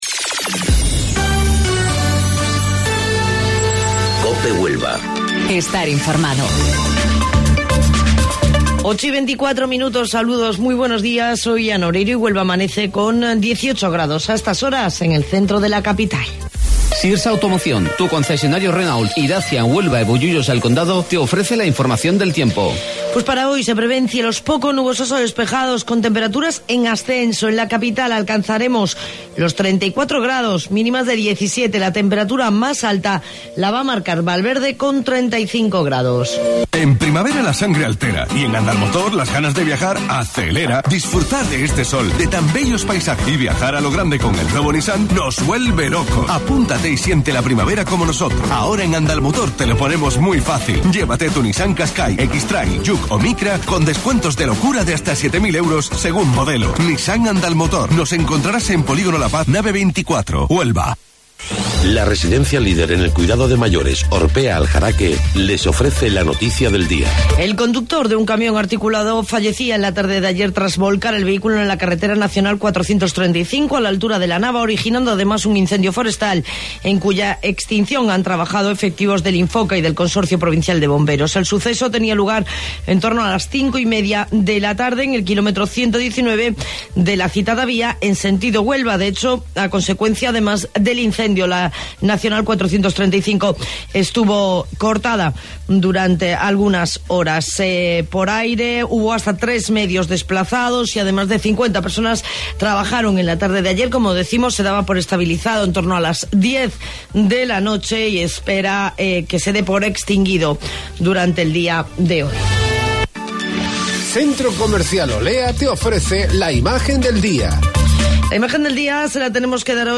AUDIO: Informativo Local 08:25 del 28 de Mayo